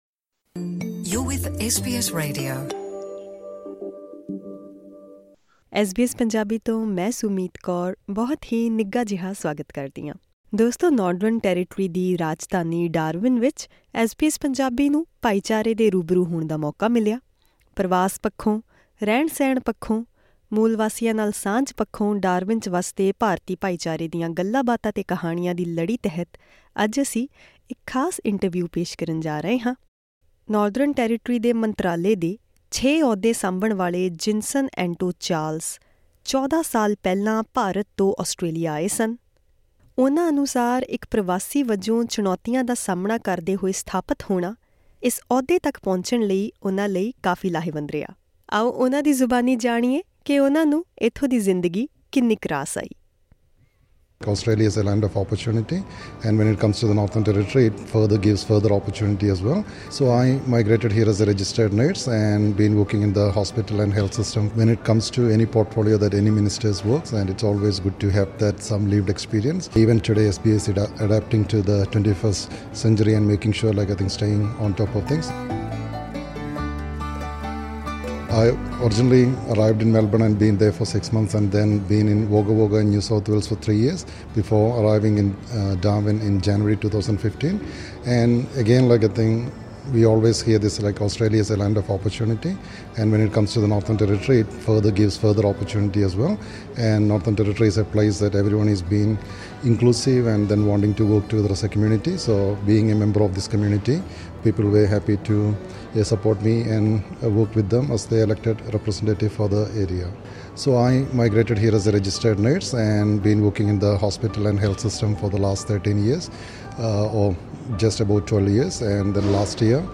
ਪਰਵਾਸ, ਰਹਿਣ ਸਹਿਣ ਅਤੇ ਮੂਲਵਾਸੀਆਂ ਨਾਲ ਸਾਂਝ ਪੱਖੋਂ ਡਾਰਵਿਨ 'ਚ ਵੱਸਦੇ ਭਾਰਤੀ ਭਾਈਚਾਰੇ ਦੀਆਂ ਗੱਲਾਬਾਤਾਂ ਤੇ ਕਹਾਣੀਆਂ ਦੀ ਲੜੀ ਤਹਿਤ ਇਹ ਖਾਸ ਇੰਟਰਵਿਊ ਕੀਤੀ ਗਈ ਹੈ।